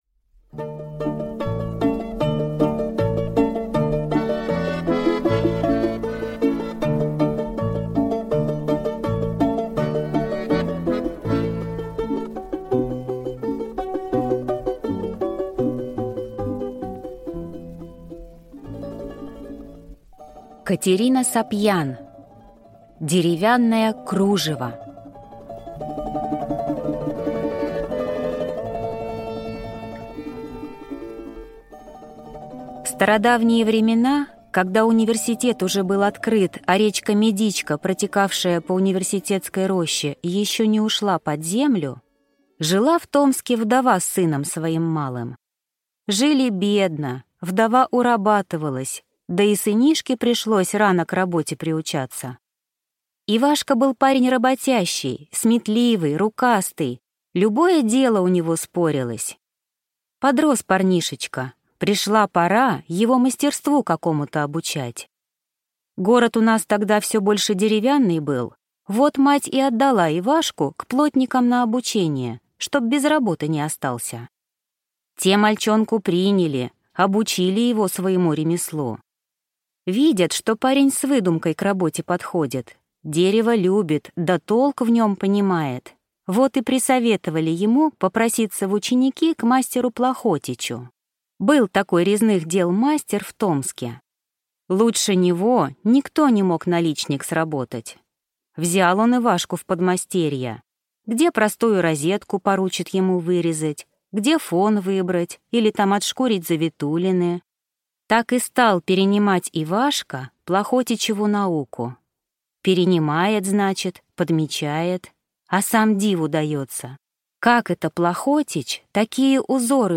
Аудиокнига Деревянное кружево | Библиотека аудиокниг